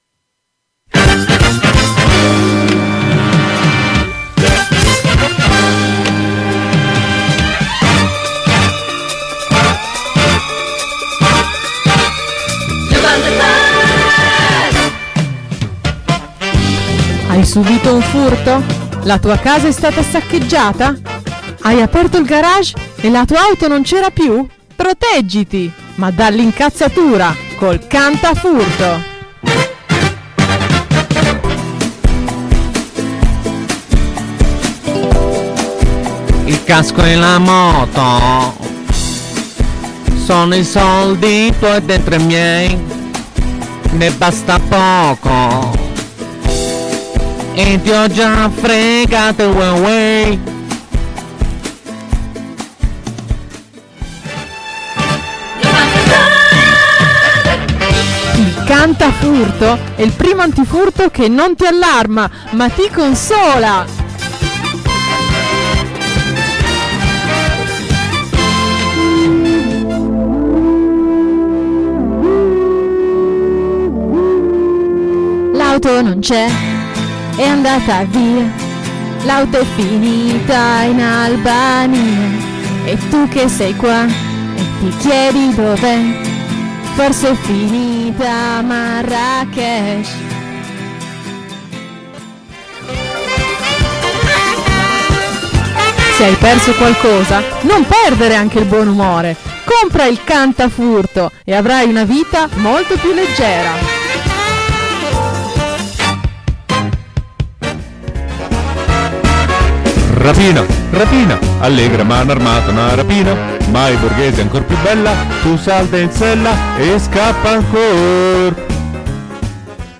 Spot Cantifurto